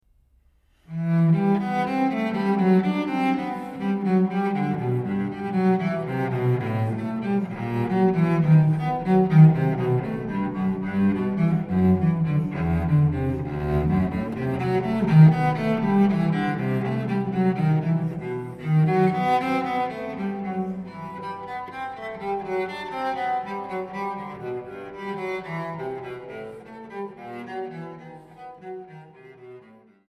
Transkiptionen für Violoncello